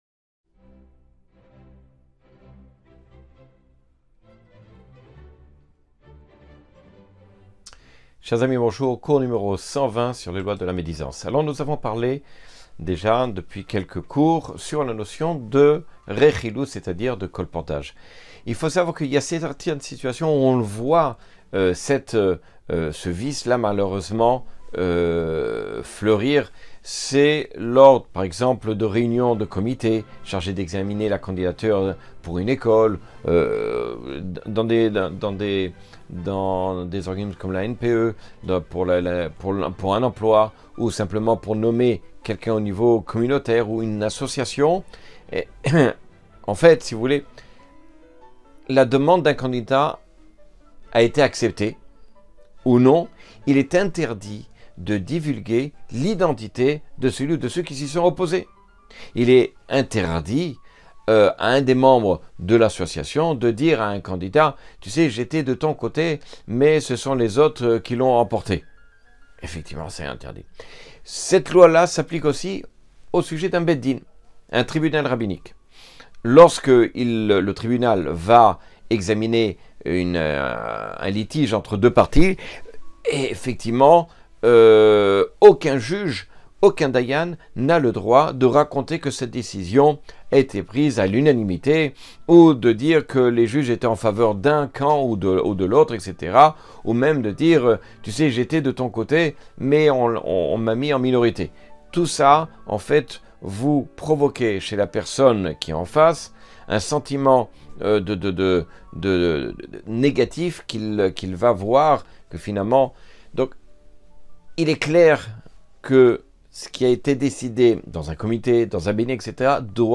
Cours 120 sur les lois du lashon hara.